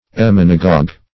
Emmenagogue \Em*men"a*gogue\, n. [Gr. ?, n. pl., menses (? in +